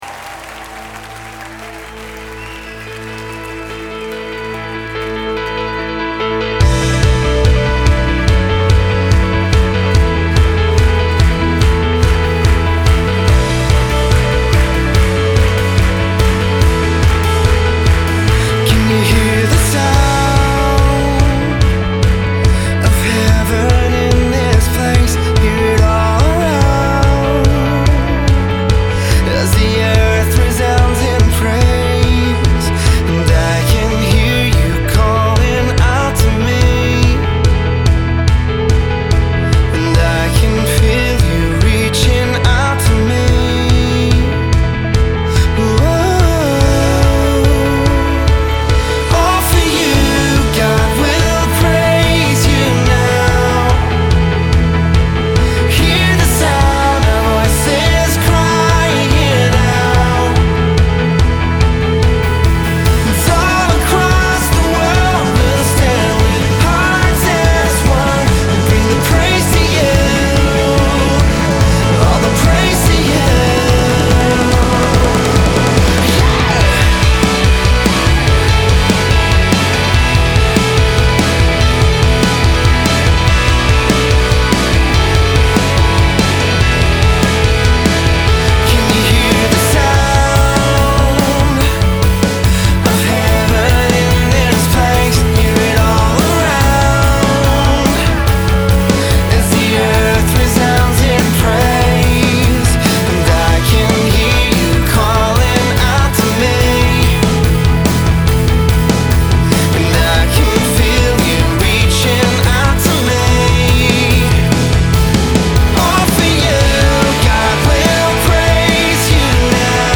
420 просмотров 424 прослушивания 47 скачиваний BPM: 144